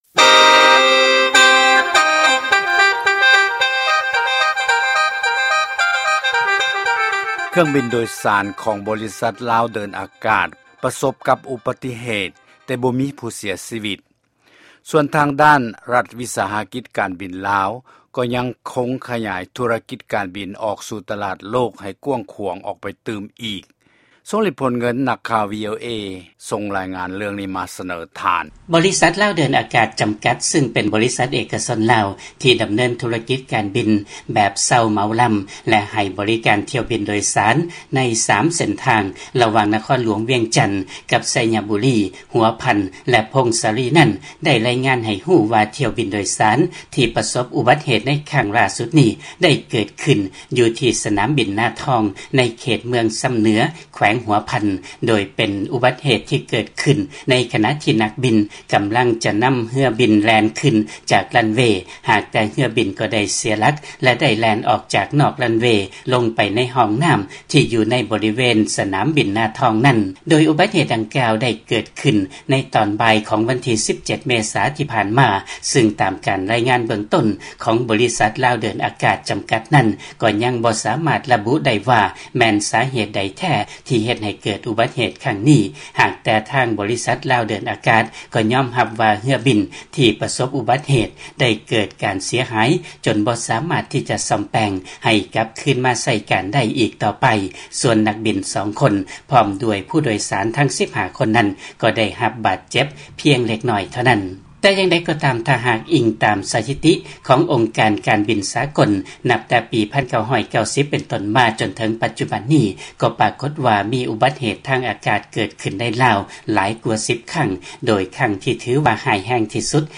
ຟັງລາຍງານ ກ່ຽວກັບການບິນລາວ